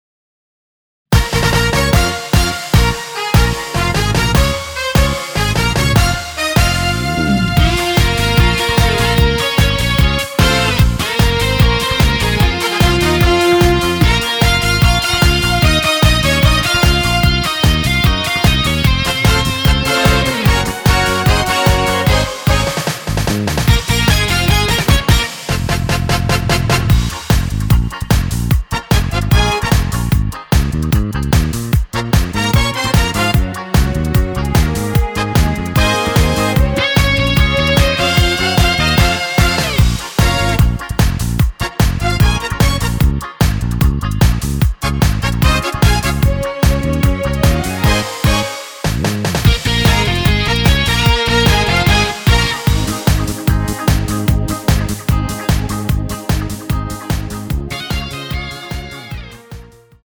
원키에서(-1)내린 MR입니다.
Bbm
앞부분30초, 뒷부분30초씩 편집해서 올려 드리고 있습니다.
중간에 음이 끈어지고 다시 나오는 이유는